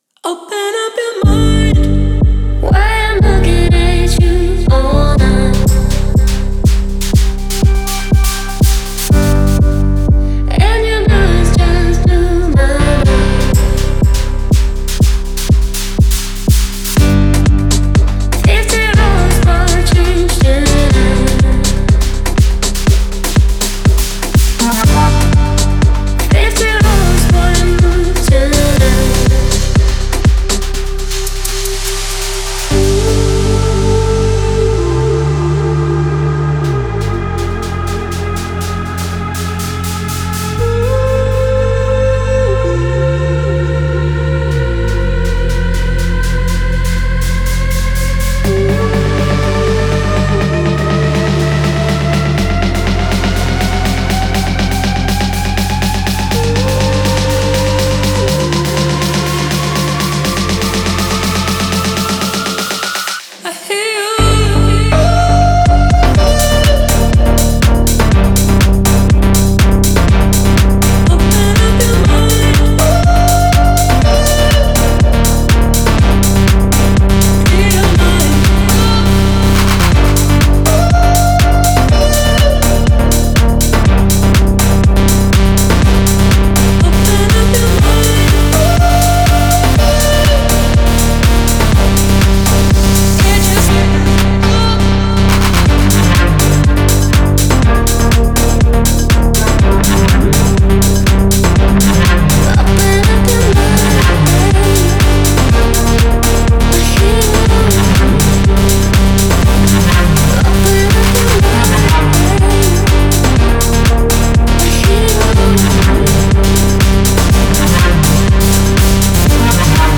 это энергичная электронная композиция в жанре EDM